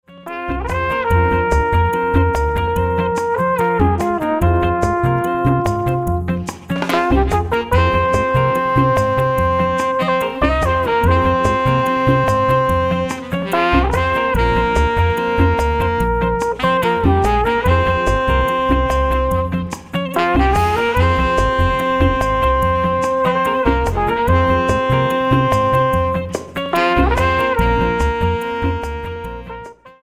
145 BPM